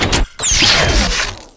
switchon.wav